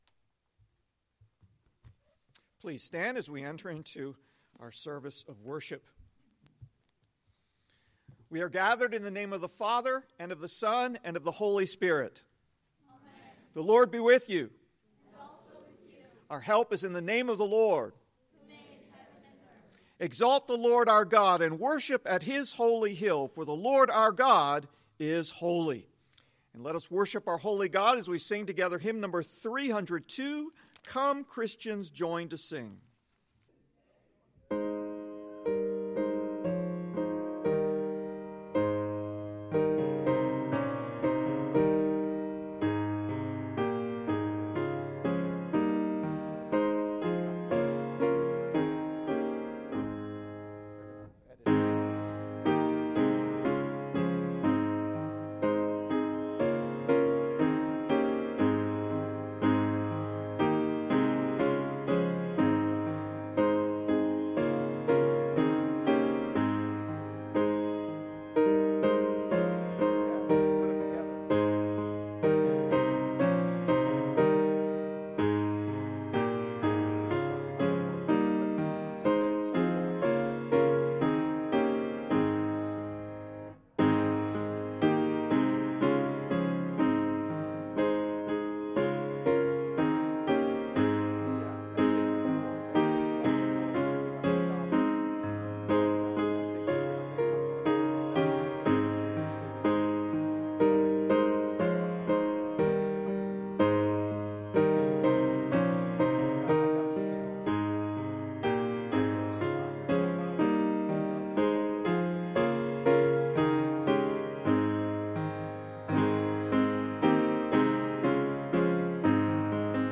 Psalm 122 celebrates the joy of corporate worship -- an appropriate text for the first Sunday we've been able to gather again. For the sake of those not able to attend, the entire service has been posted this week.